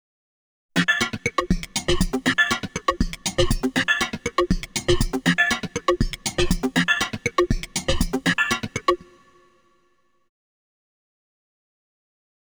Index of /90_sSampleCDs/Inspiration_Zone/rhythmic loops
05_wavesequence_2_OS.wav